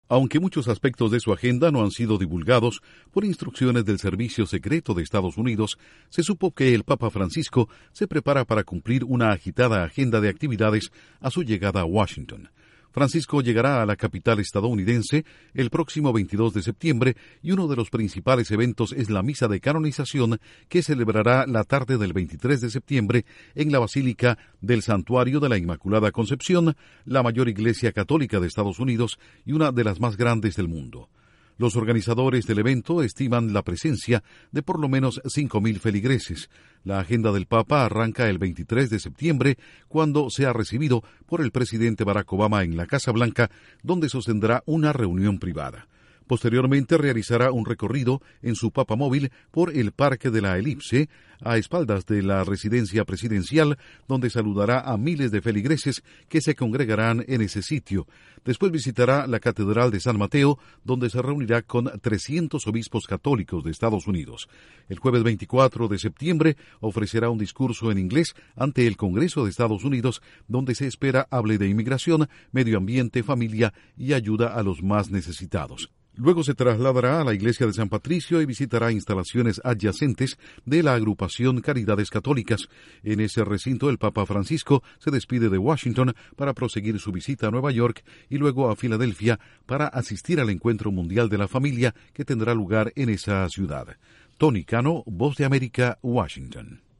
El papa Francisco cumplirá una extensa agenda en Washington. Cinco mil personas asistirán a la misa de canonización del fraile español Junípero Serra en la Basílica del Santuario de la Inmaculada Concepción. Informa desde la Voz de América en Washington